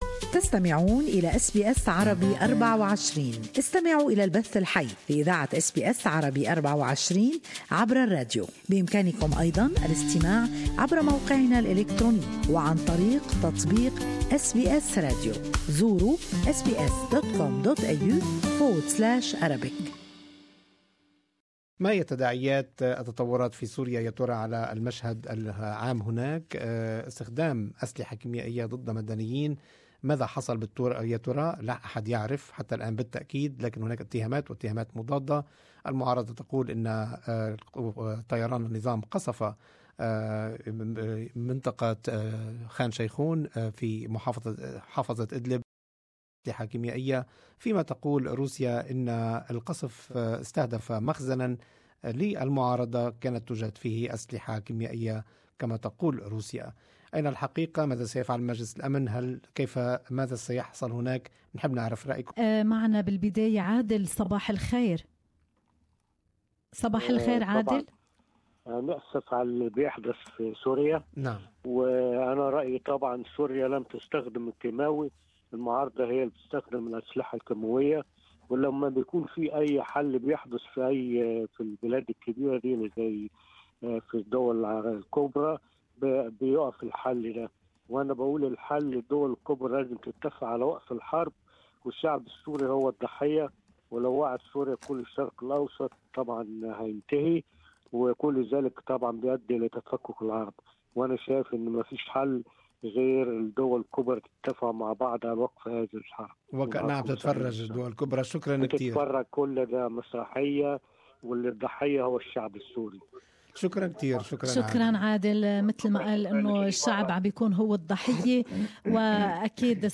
Good Morning Australia listeners share their opinions.